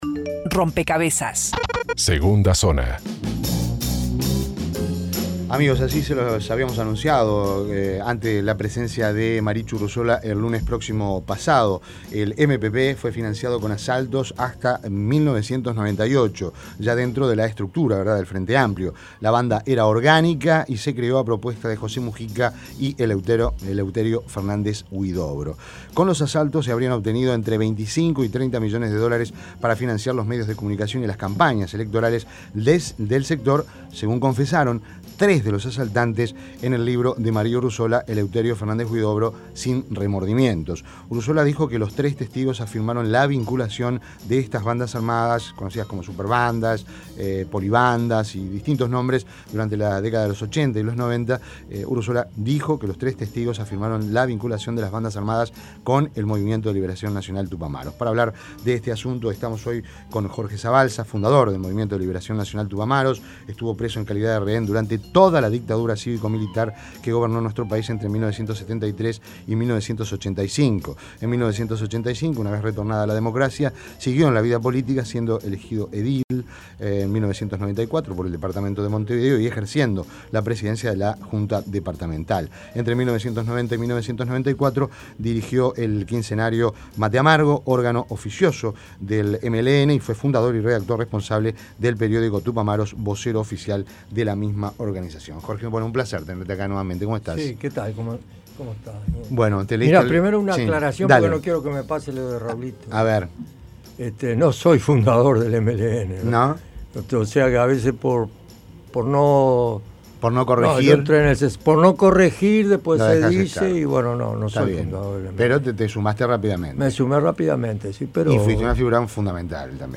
Descargar Audio no soportado El exmiembro del Movimiento de Liberación Nacional (MLN) y Movimiento de Participación Popular comenzó la entrevista tomando distancia del vicepresidente Sendic.